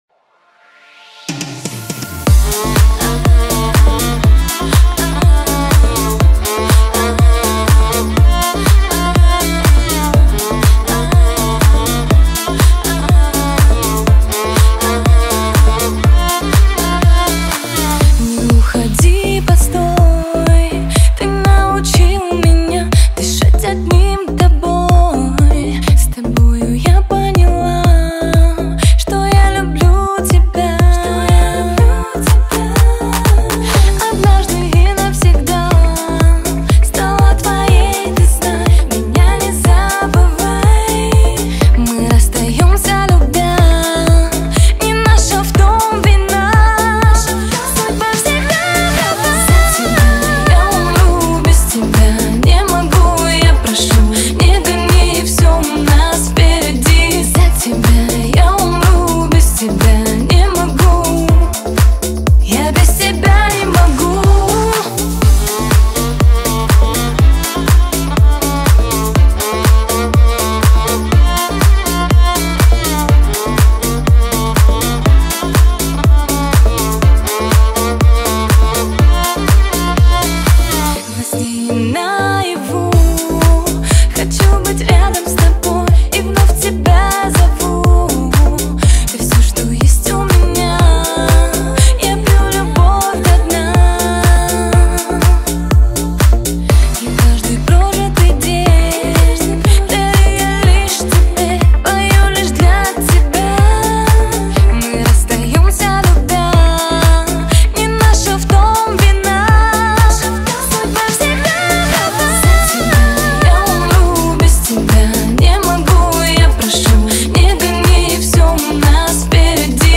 это динамичная композиция в жанре электронной музыки